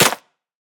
Minecraft Version Minecraft Version 25w18a Latest Release | Latest Snapshot 25w18a / assets / minecraft / sounds / block / muddy_mangrove_roots / step2.ogg Compare With Compare With Latest Release | Latest Snapshot
step2.ogg